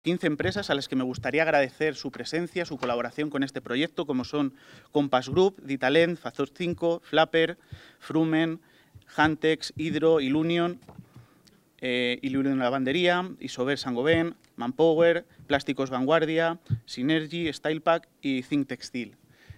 Declaraciones del alcalde Miguel Óscar Aparicio